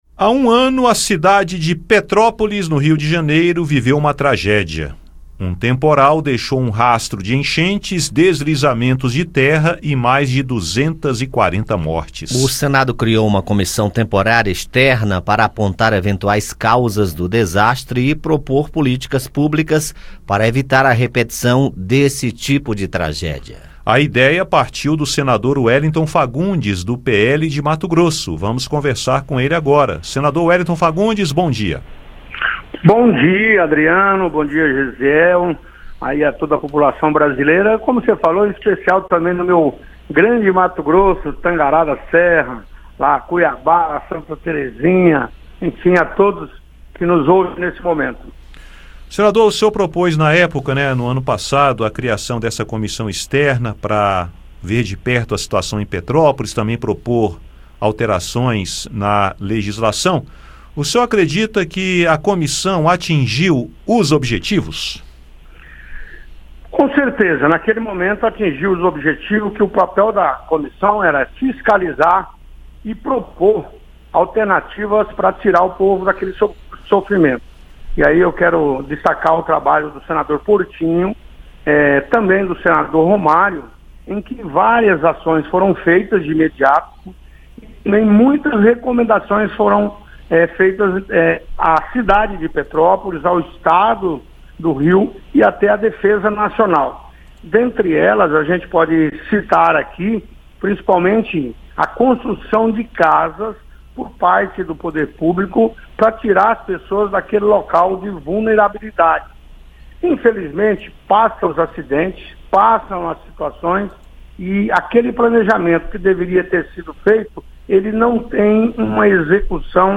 O senador Wellington Fagundes (PL-MT) que propôs a comissão, fala sobre os trabalhos realizados, o que tem sido feito em termos de legislação e o que o Congresso Nacional tem proposto em termos de orçamento para evitar novas tragédias. Confira a entrevista.